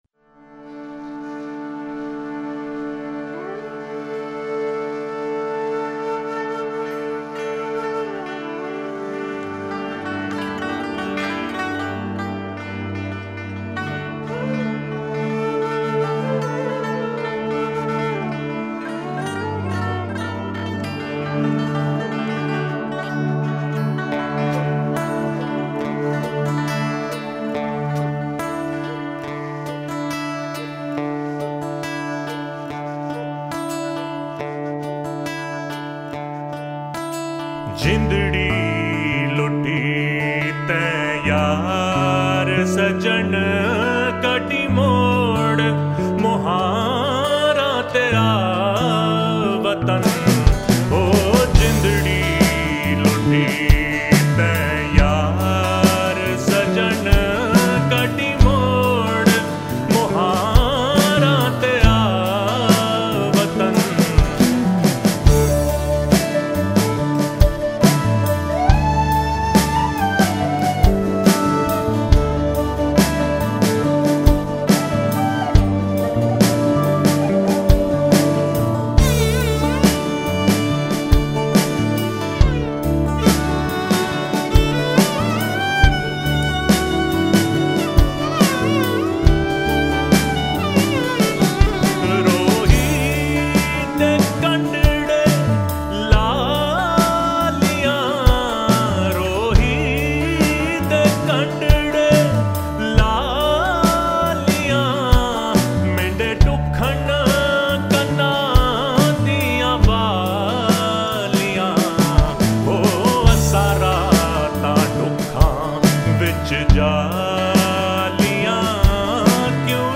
Sufi Music